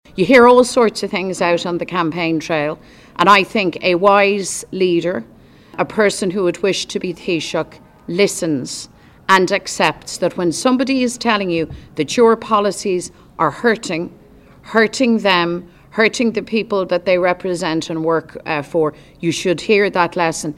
Mary Lou McDonald says politicians should be prepared to face shortcomings while canvassing: